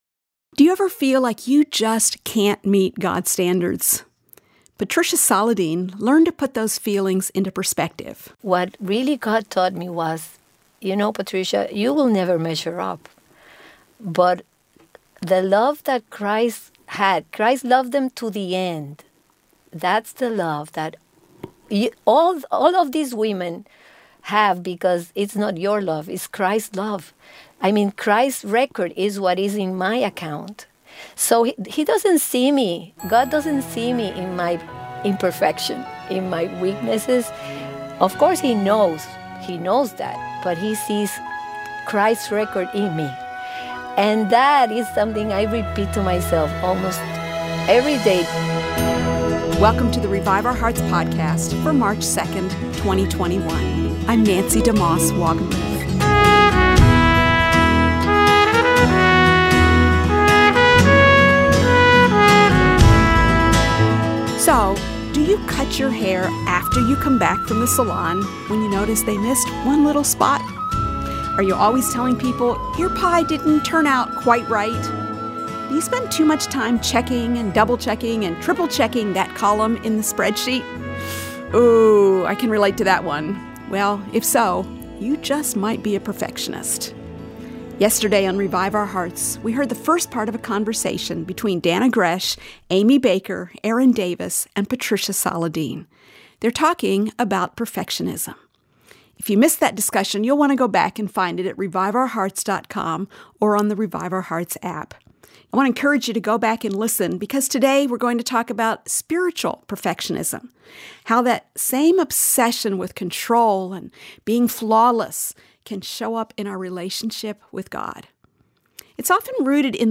Listen as a panel of women talk about how perfectionistic thinking affects our relationship with the Lord.